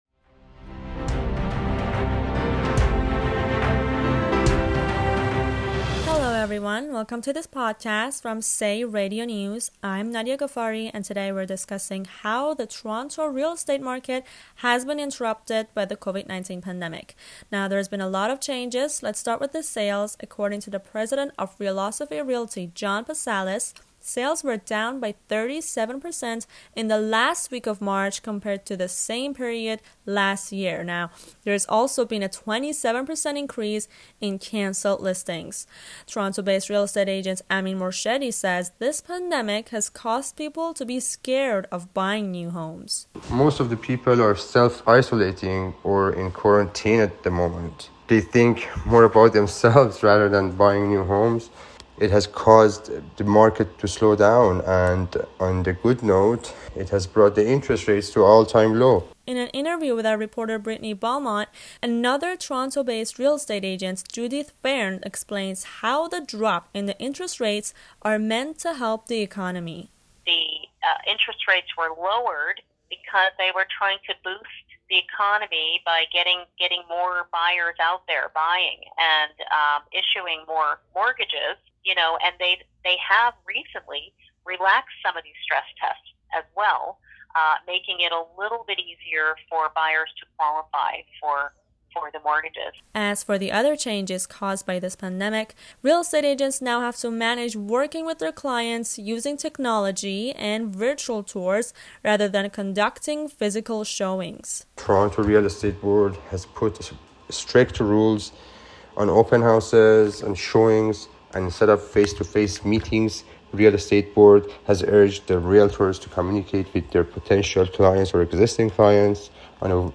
The housing market in Toronto has been severely impacted by the Covid-19 pandemic in so many ways. In this podcast we talk to Toronto based real state agents to see how they are adapting.